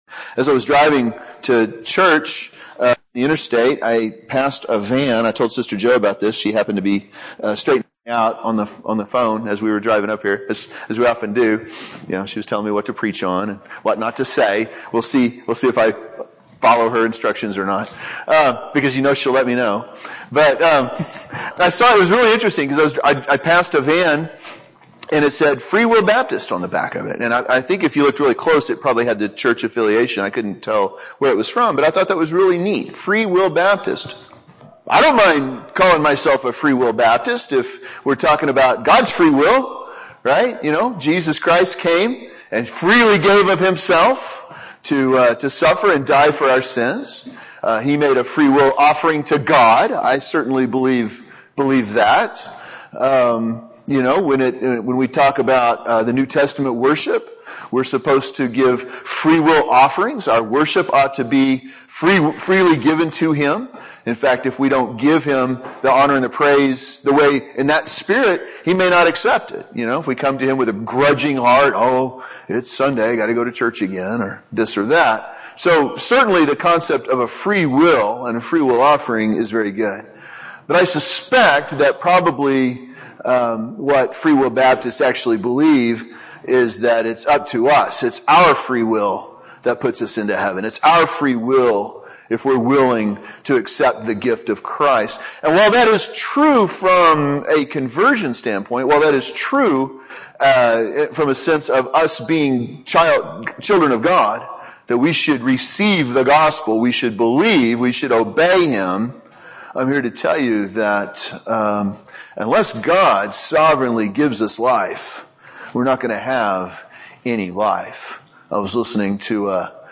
Sermons preached in other churches • Page 25
This sermon was recorded at Walnut Valley Primitive Baptist Church Located in Blanchard, Oklahoma